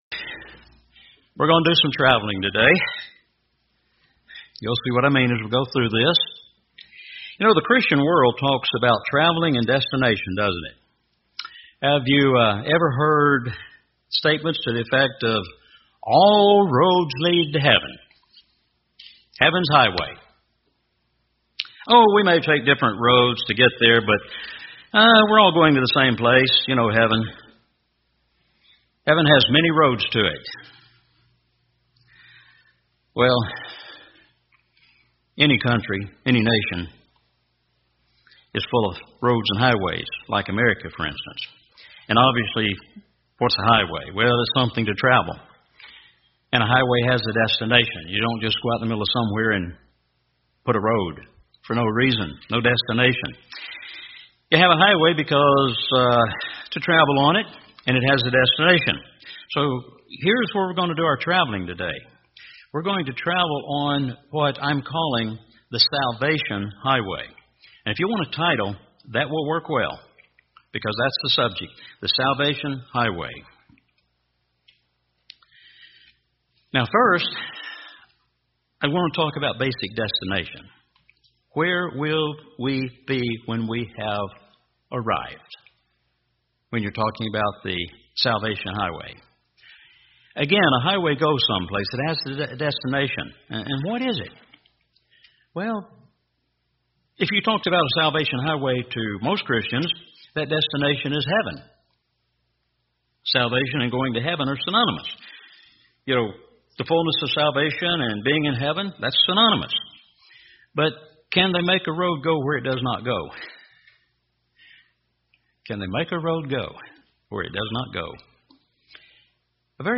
You will really enjoy this sermon about the road to salvation.